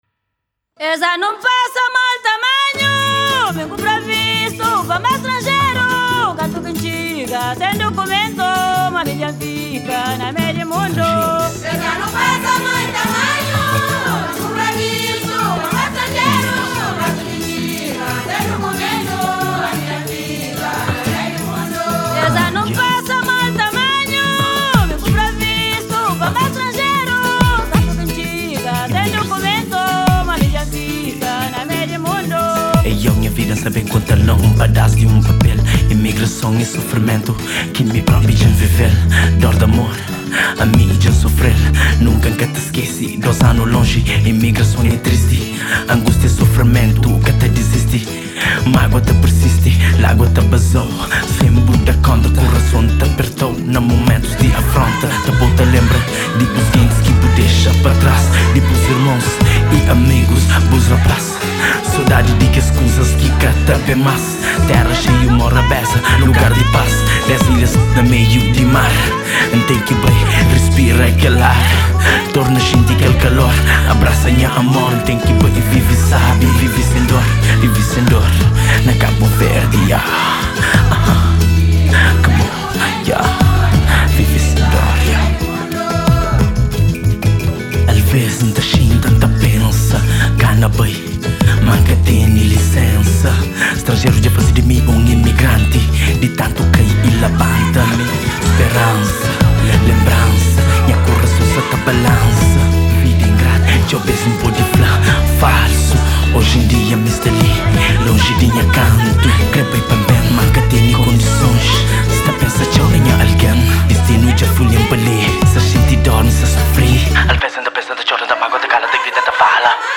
Folk / Tradicional / World music